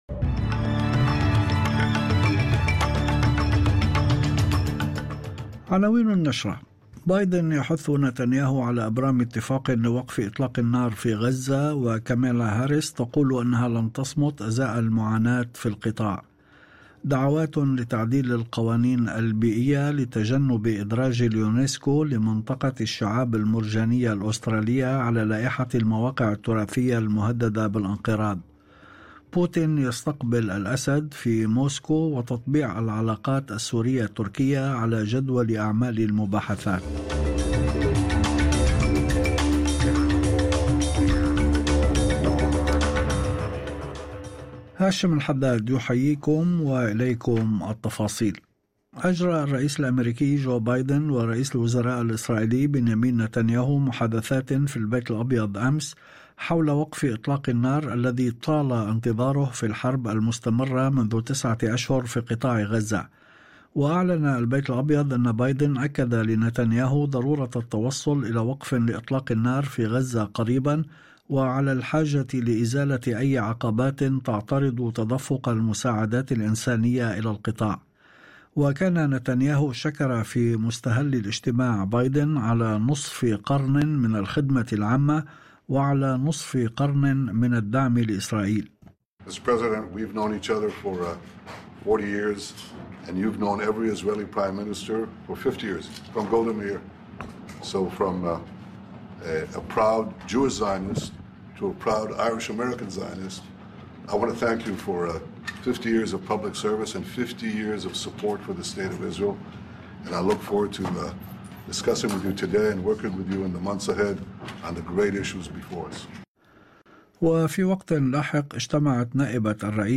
نشرة أخبار المساء 26/07/2024